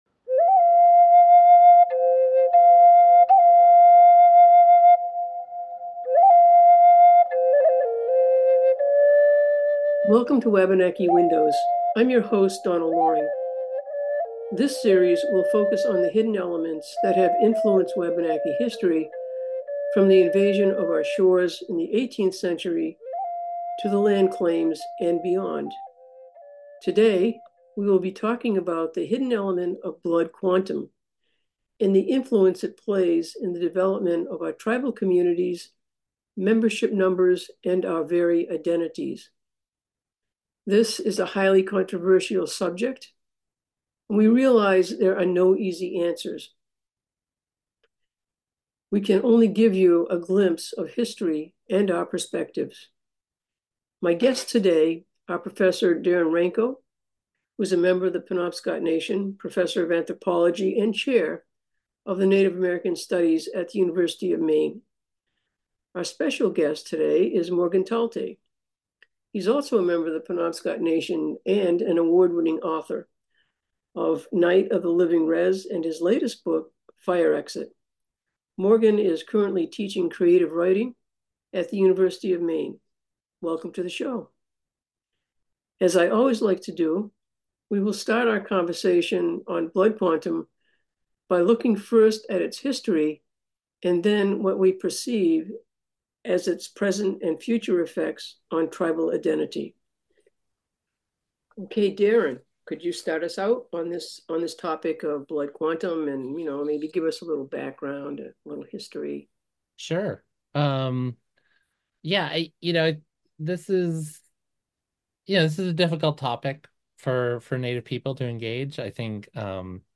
Wabanaki Windows is a monthly community radio public affairs program, hosted by Donna Loring, Penobscot Indian Nation Tribal Elder. In this episode Donna and her guests explore Blood Quantum and the influence it has played in the development of Tribal Communities, membership numbers, and their very identities.